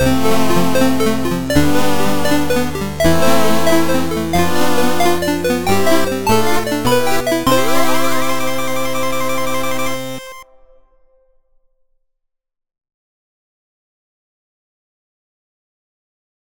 pull-sword.ogg